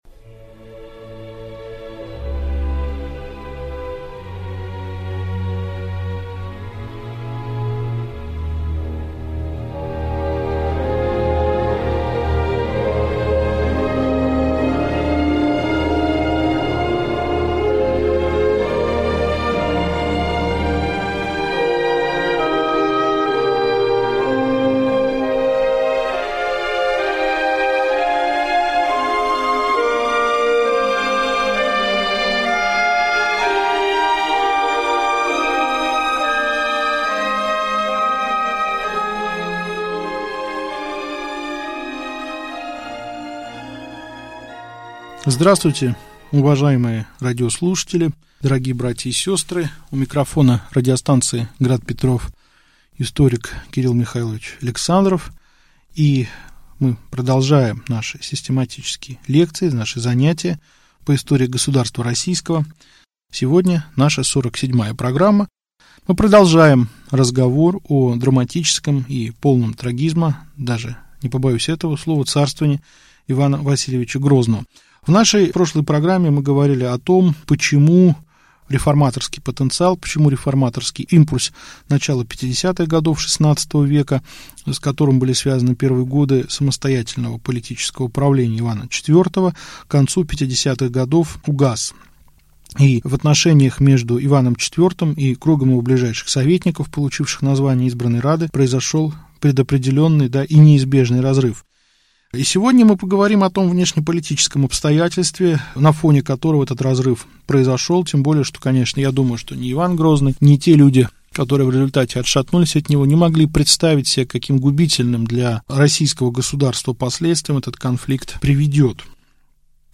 Аудиокнига Лекция 47. Ливонская война. Опала Адашева и Сильвестра | Библиотека аудиокниг